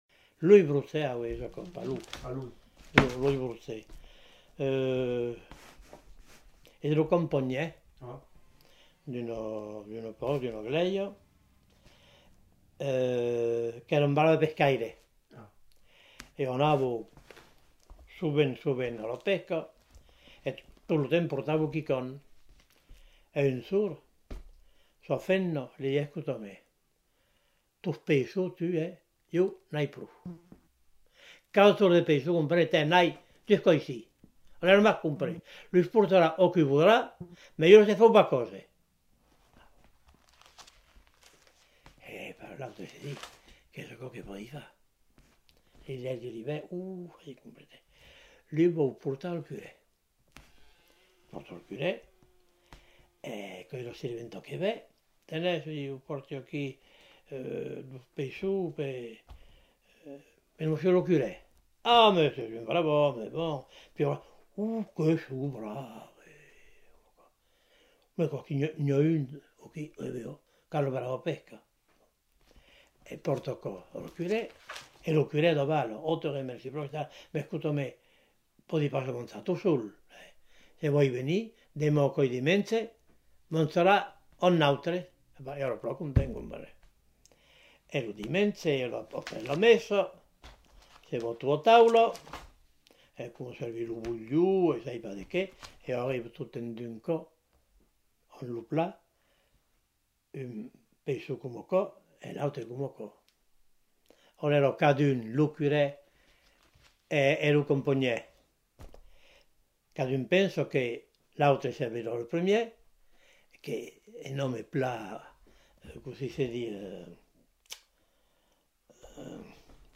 Aire culturelle : Quercy
Lieu : Rocamadour
Genre : conte-légende-récit
Effectif : 1
Type de voix : voix d'homme
Production du son : lu